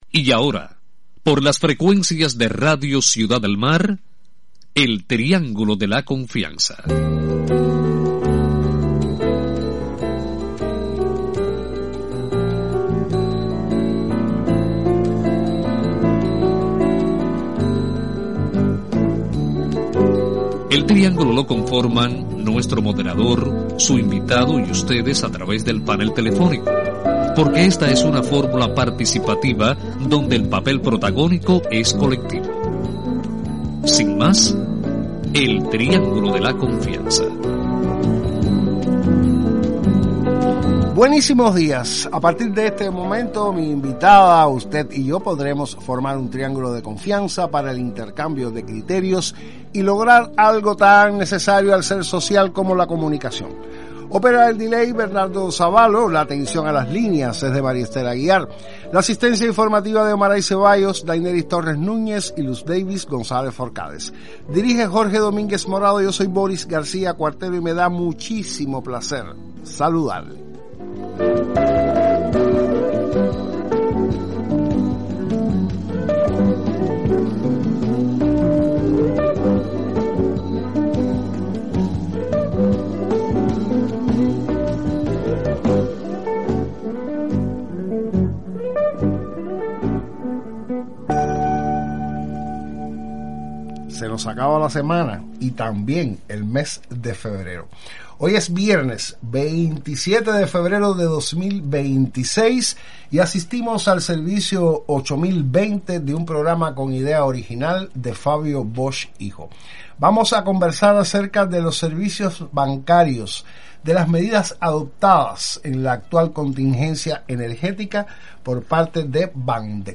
intercambia con los oyentes del Triángulo de la confianza acerca de las medidas en las sucursales de esa red bancaria para enfrentar la actual situación energética.